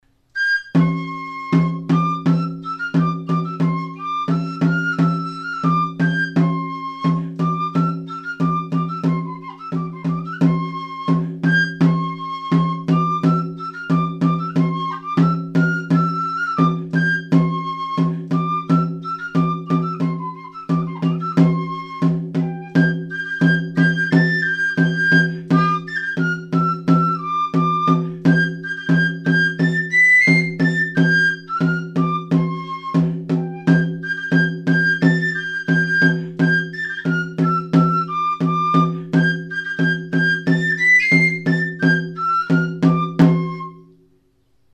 Instruments de musiqueTXISTUA
Aérophones -> Flûtes -> Á Bec (á une main)
Enregistré avec cet instrument de musique.
Hiru zuloko flauta zuzena da.